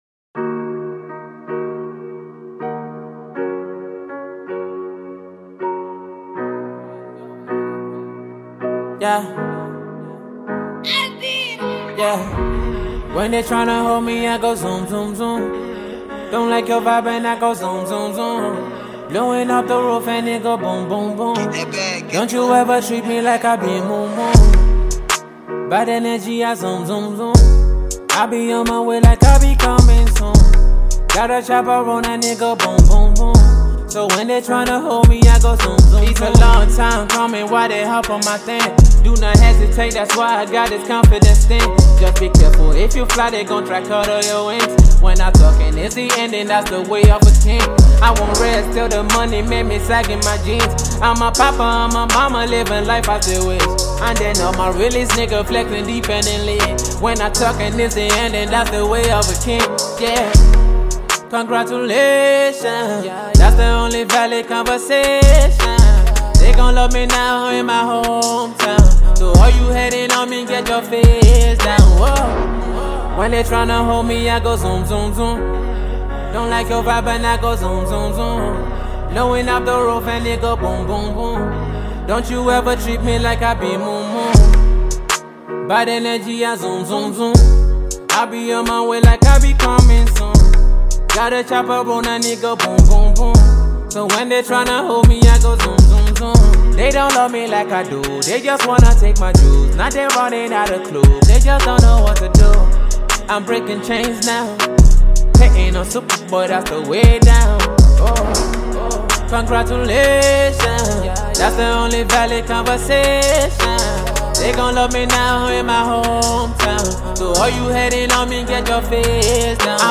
infectious flow